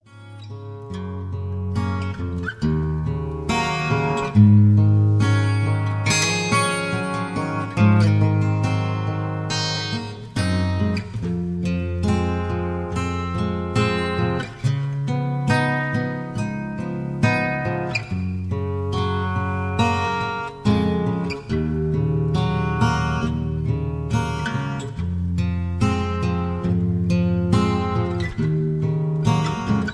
(Key-D)
Tags: backing tracks , irish songs , karaoke , sound tracks